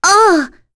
Rodina-Vox_Damage_01.wav